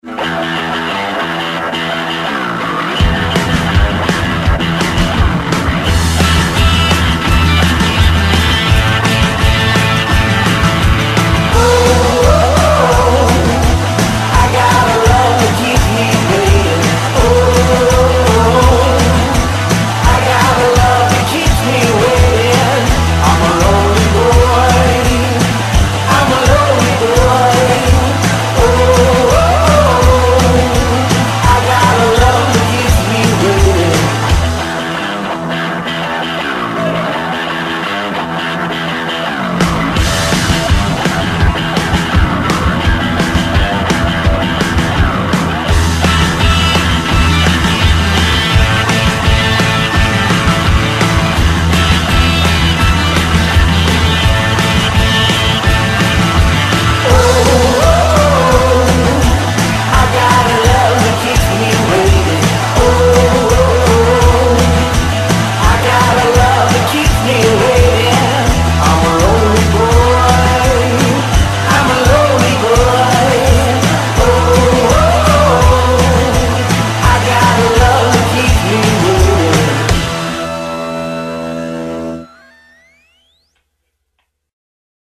Le Jingle